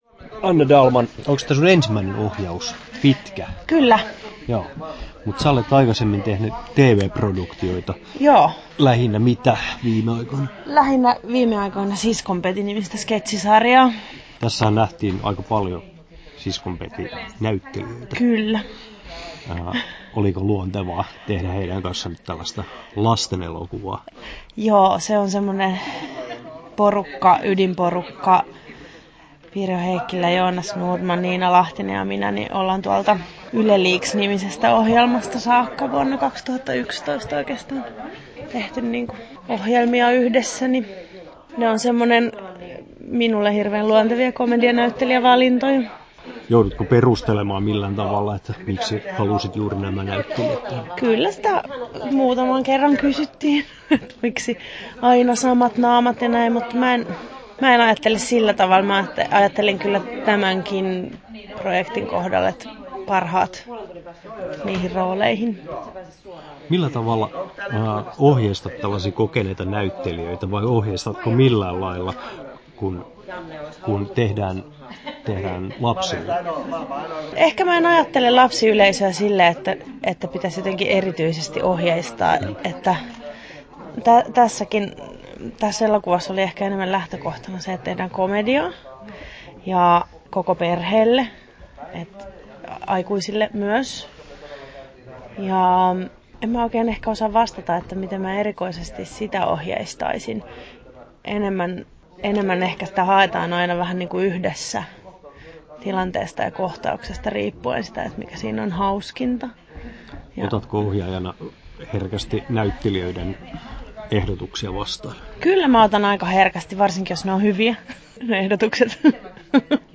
Haastattelussa
8'51" Tallennettu: 13.11.2017, Turku Toimittaja